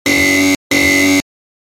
Wrong Sound Effect
Sharp and recognizable buzzer tone that instantly signals an error or wrong answer. Use this clear, punchy sound effect to highlight incorrect actions in games, apps, TikTok, Reels, and YouTube Shorts.
Wrong-sound-effect.mp3